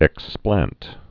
(ĕk-splănt)